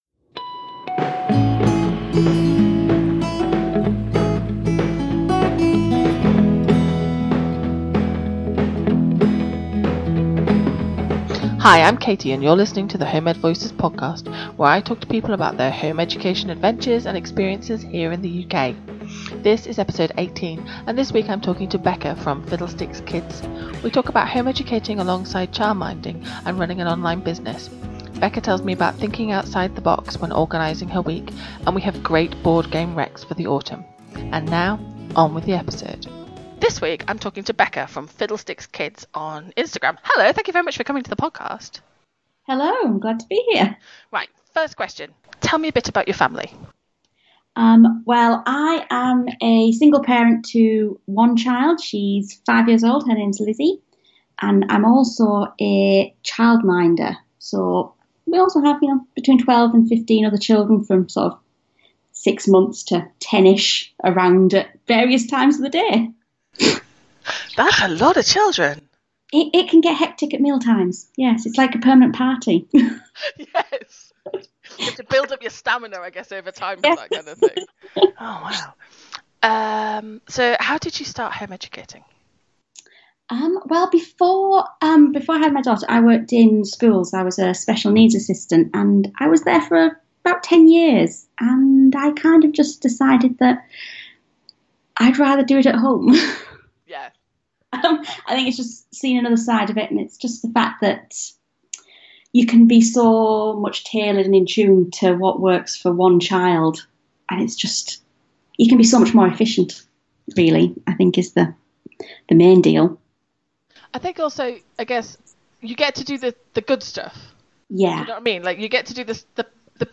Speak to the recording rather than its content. The Home Ed Voices Podcast is a UK-based Home Education Podcast that profiles the lives of home ed families.